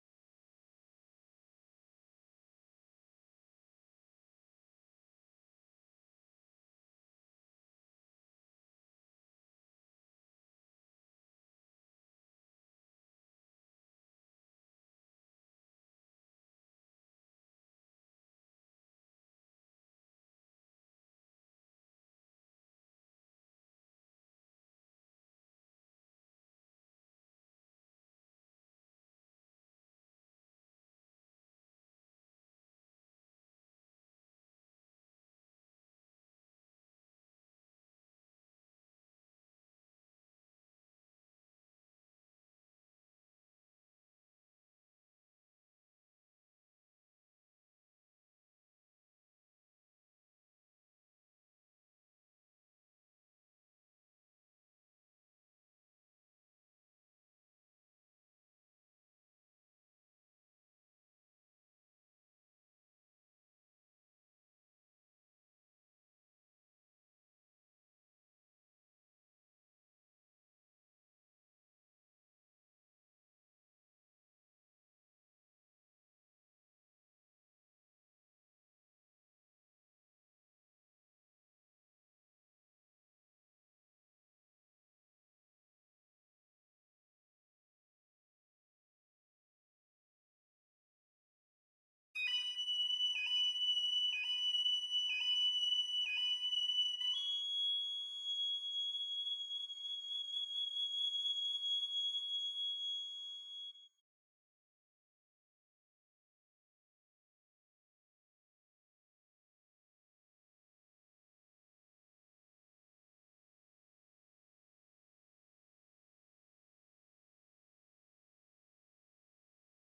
1. Piccolo (Piccolo/Normal)
Holst-Mars-29-Piccolo_1.mp3